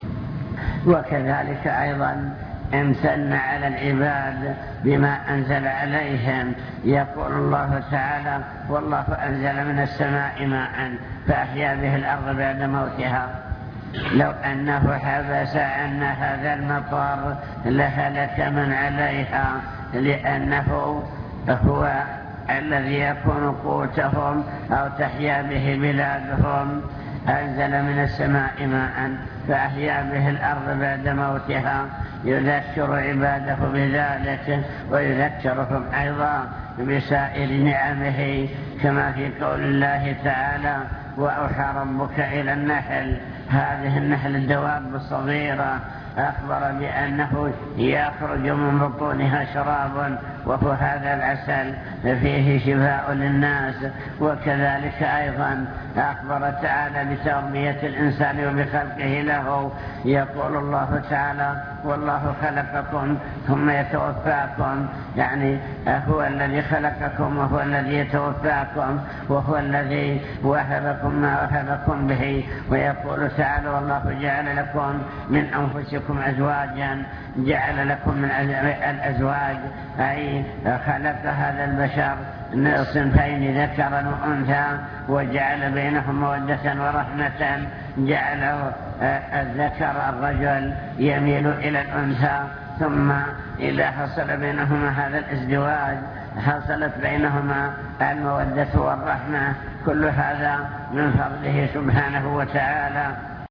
المكتبة الصوتية  تسجيلات - محاضرات ودروس  محاضرة بعنوان شكر النعم (2) امتنان الله تعالى على عباده بما أنعم عليهم